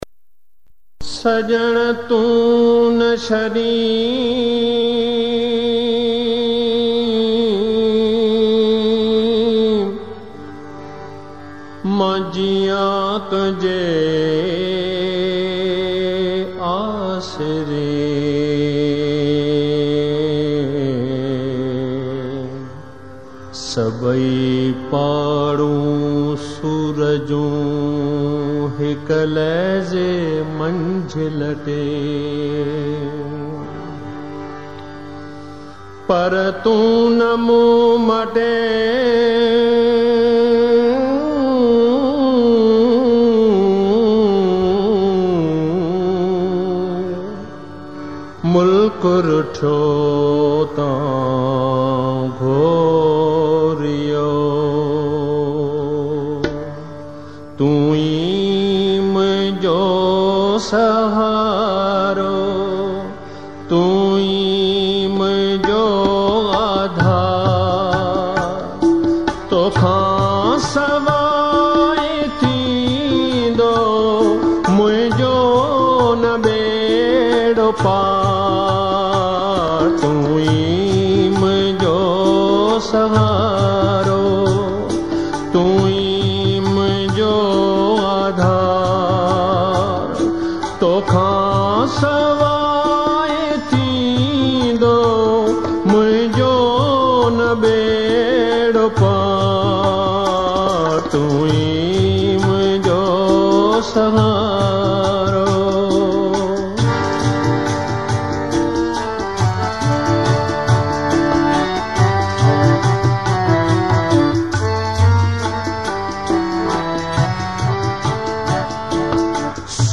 Classical Spiritual Sindhi Songs